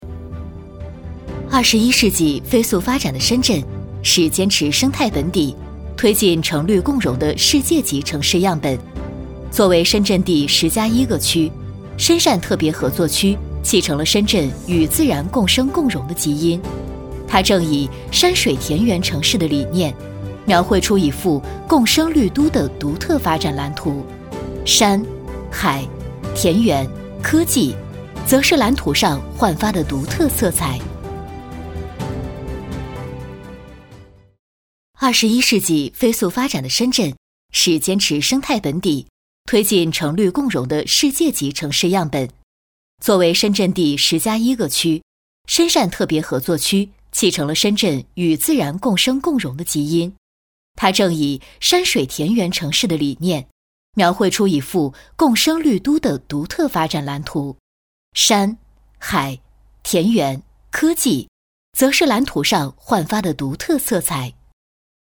女49-（展馆）深汕特别合作区规划展览馆档案馆
女49双语配音 v49
女49--展馆-深汕特别合作区规划展览馆档案馆.mp3